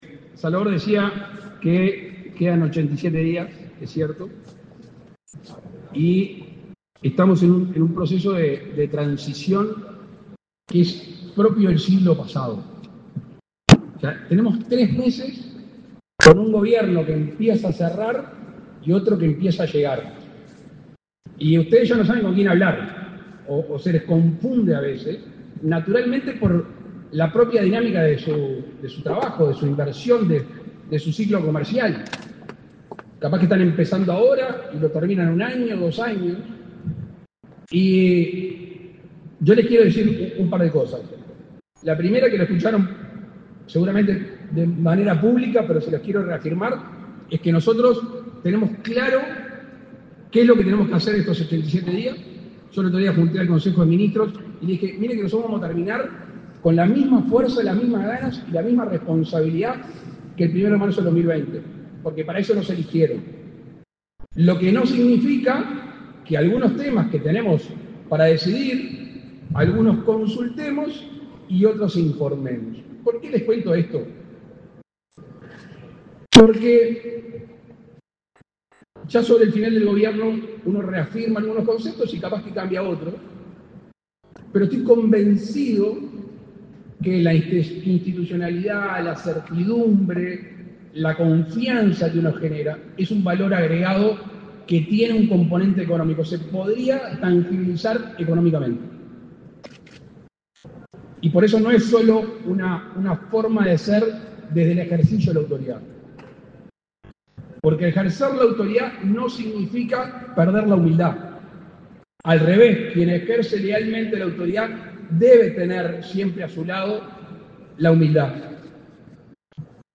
Palabras del presidente de la República, Luis Lacalle Pou
El presidente de la República, Luis Lacalle Pou, participó, este 4 de diciembre, en la ceremonia de Reconocimiento al Esfuerzo Exportador 2024.